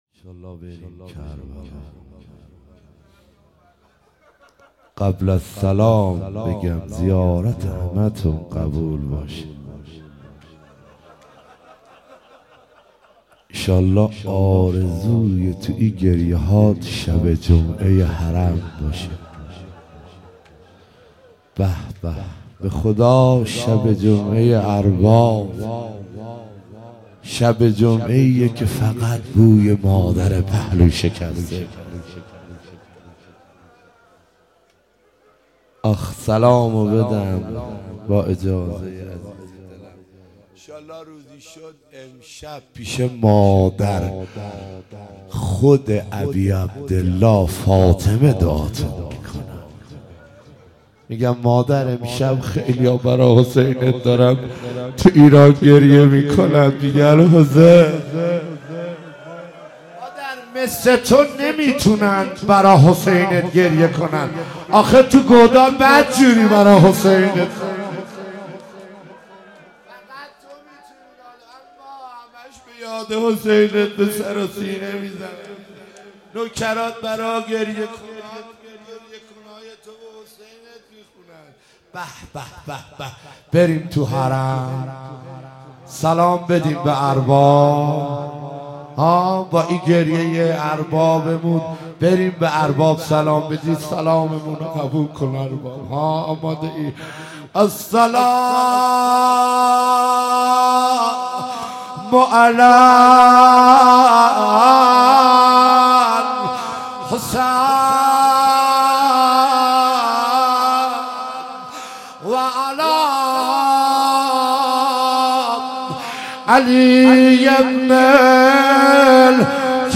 روضه امام حسین ع - سعید حدادیان با ترافیک رایگان